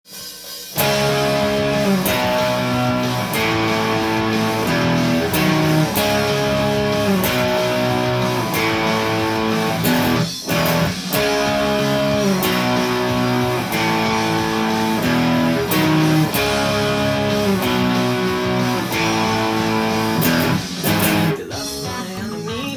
音源にあわせて譜面通り弾いてみました
パワーコードばかりにアレンジしました。
リズムも必ず誰でも弾ける全音符ばかりです。
たまに４分休符や８分音符が出てきますが